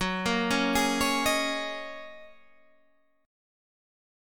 F#6add9 chord